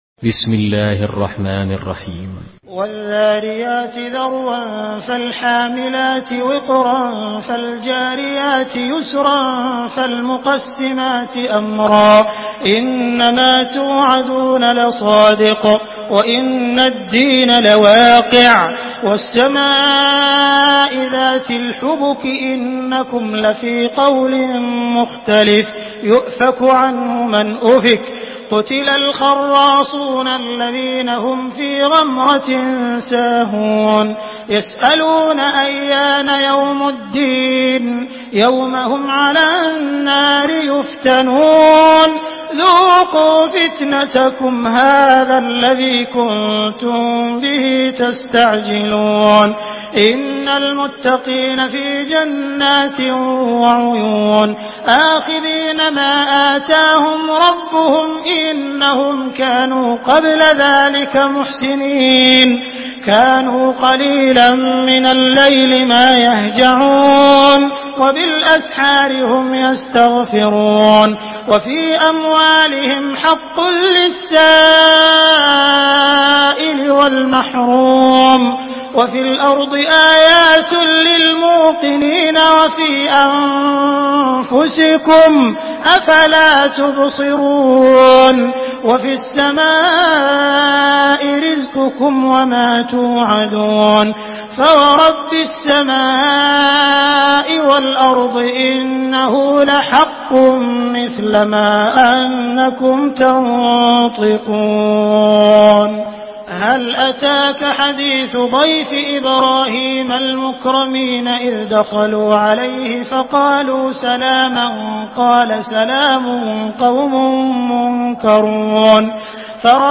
Surah الذاريات MP3 in the Voice of عبد الرحمن السديس in حفص Narration
Listen and download the full recitation in MP3 format via direct and fast links in multiple qualities to your mobile phone.
مرتل